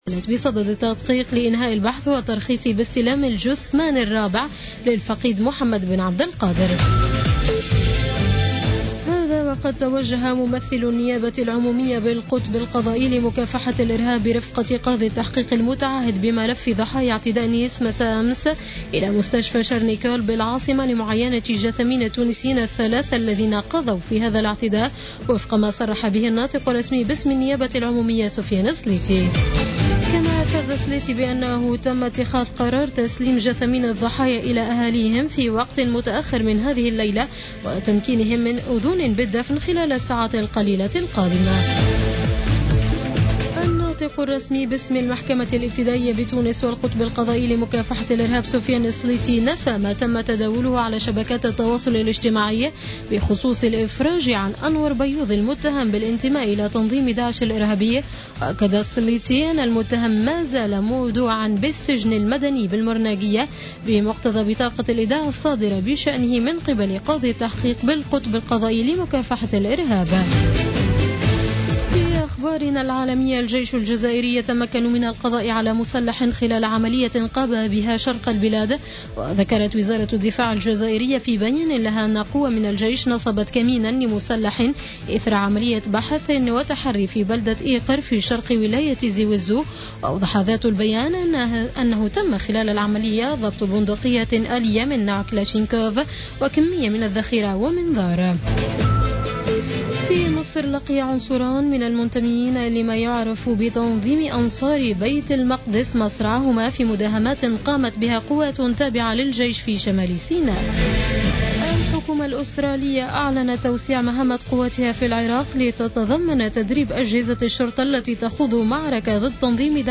نشرة أخبار منتصف الليل ليوم الاربعاء 20 جويلية 2016